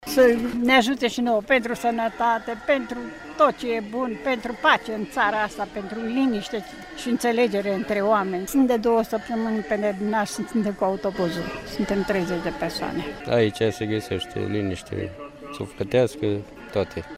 La Mânăstirea Nicula din judeţul Cluj, devenită unul dintre cele mai impresionante locuri de pelerinaj din Europa, mii de credincioşi din toată ţara s-au adunat pentru a se ruga Maicii Domnului şi pentru a vedea icoana Făcătoare de Minuni.
vox-nicula.mp3